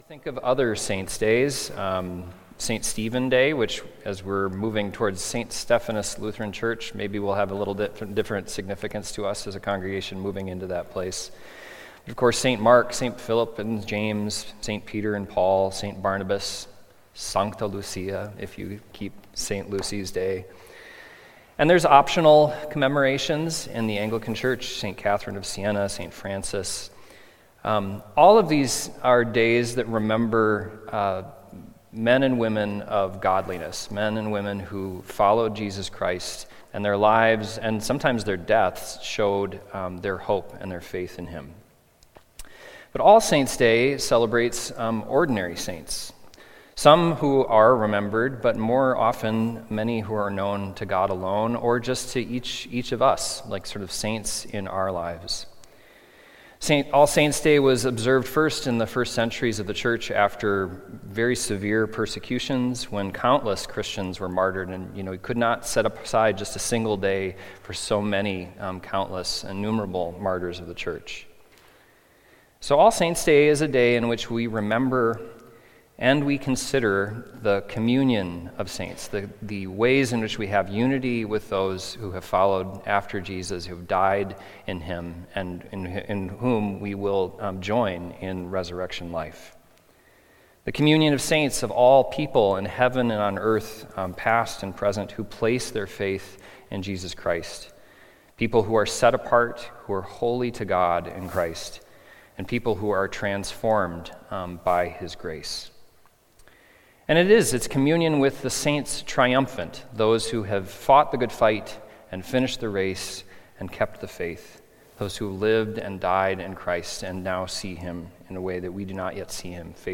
Sunday Worship–Nov. 2, 2025
Sermons